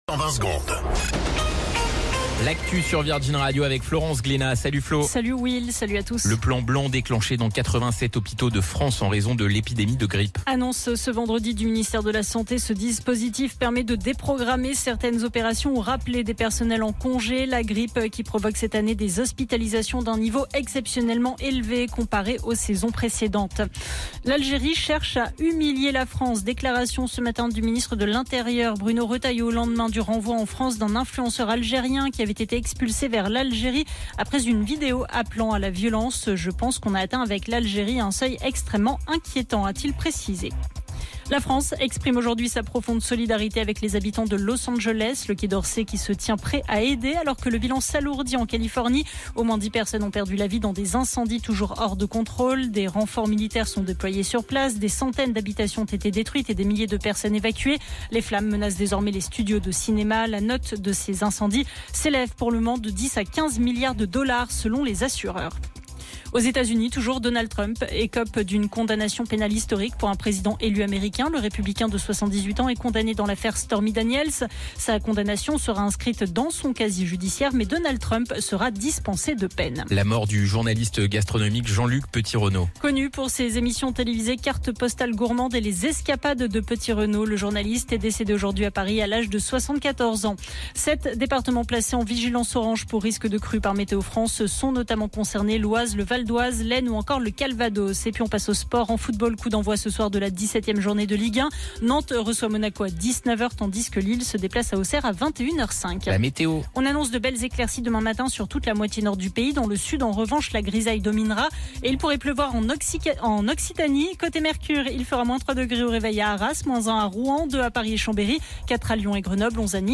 Flash Info National 10 Janvier 2025 Du 10/01/2025 à 17h10 .